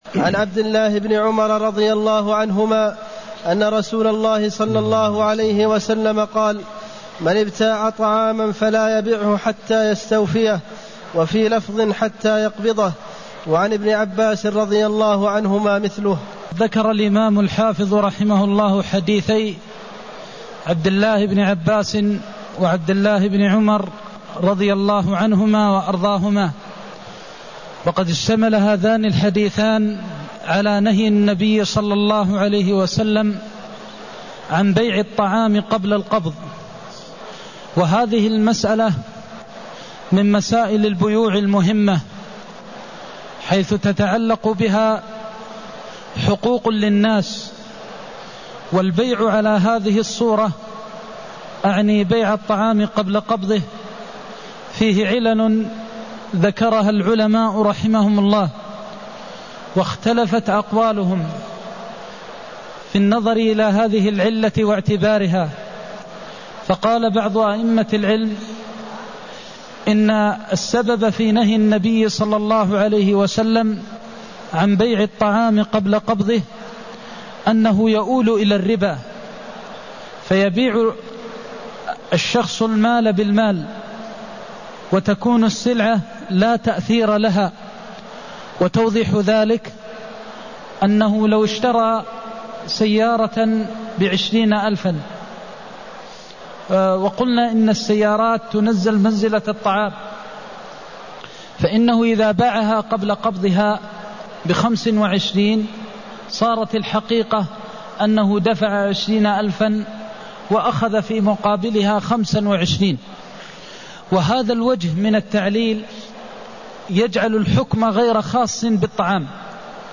المكان: المسجد النبوي الشيخ: فضيلة الشيخ د. محمد بن محمد المختار فضيلة الشيخ د. محمد بن محمد المختار نهيه عن بيع الطعام قبل القبض (254)